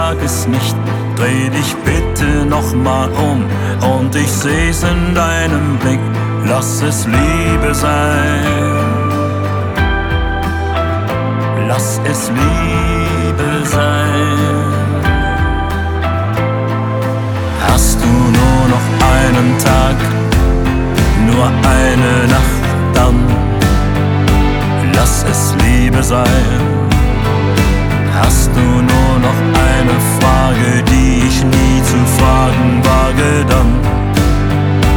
German Pop
Жанр: Поп музыка